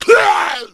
Index of /cstrike/sound/RA_Zmsounds/Pain
Hunter_PounceCancel_03.wav